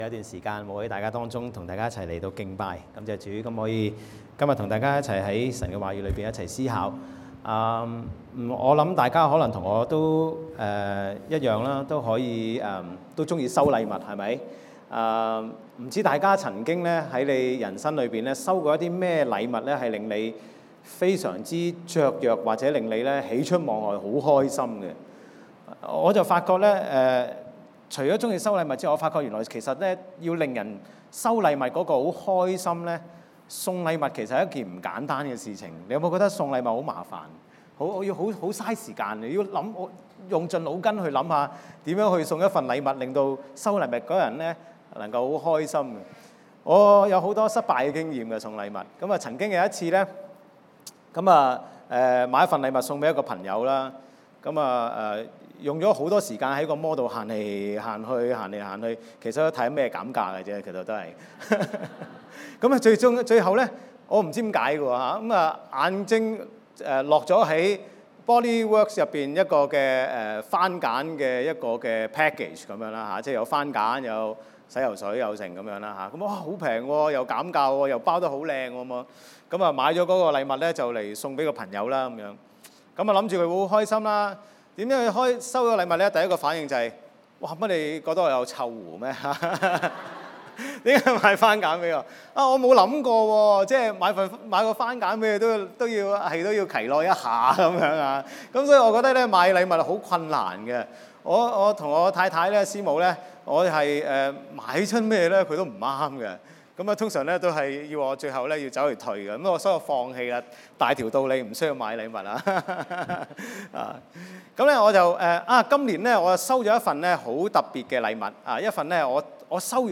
Cantonese 3rd Service, Chinese Category